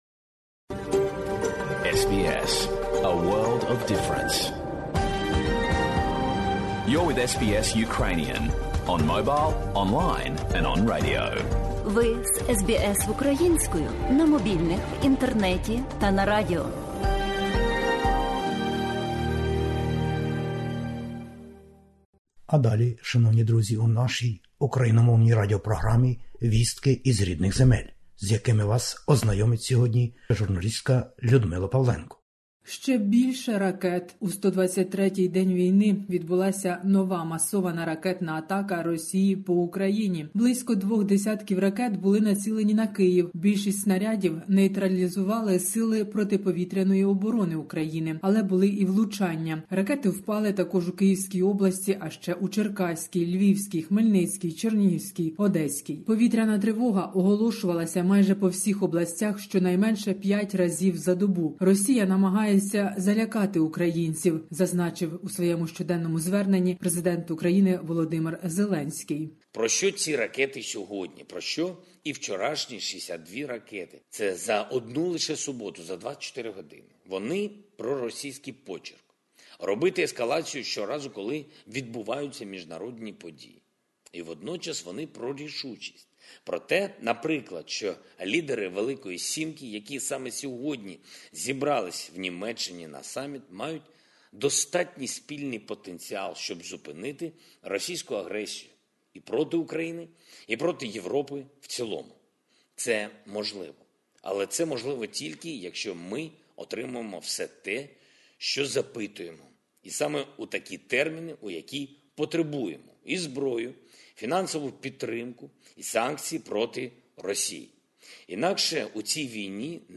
Ukraine needs a powerful modern air defense, we talk about this with partners everyday - address by President Volodymyr Zelenskyy.